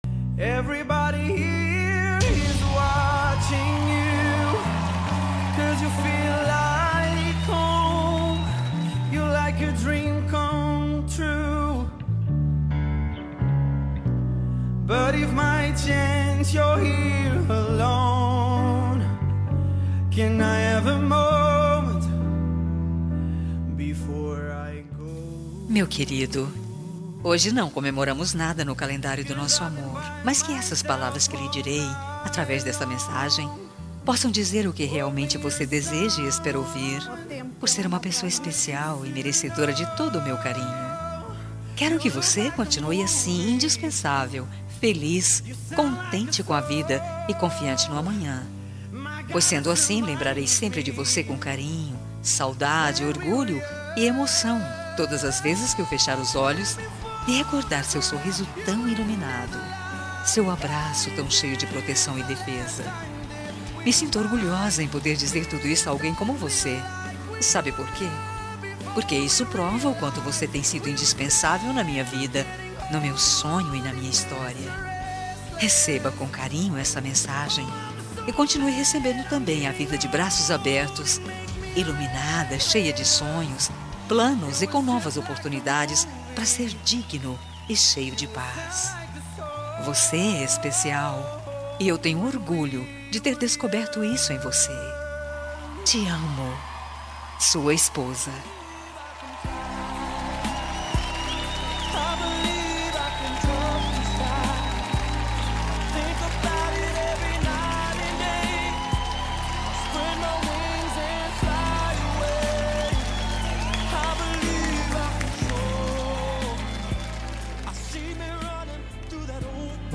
Romântica para Marido- Voz Feminina – Cód: 6720